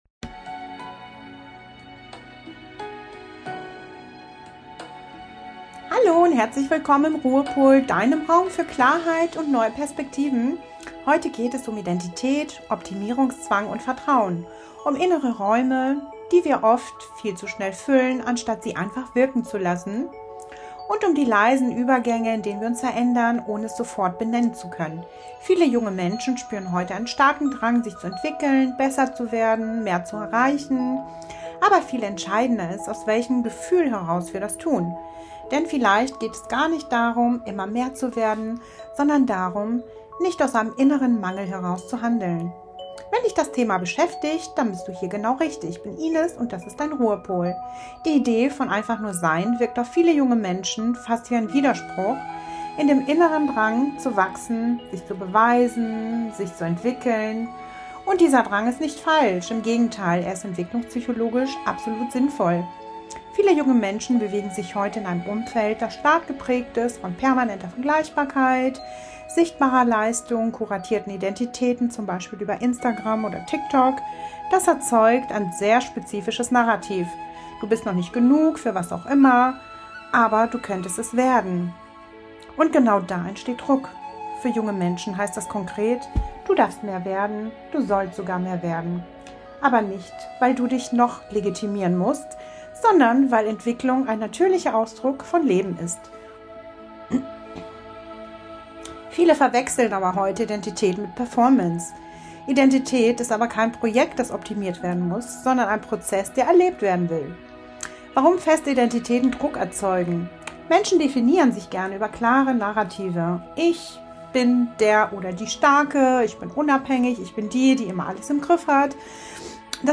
Am Ende der Folge erwartet dich eine geführte Herzraum-Meditation (Anahata) die dich dabei unterstützt, Vertrauen nicht nur zu verstehen, sondern zu fühlen.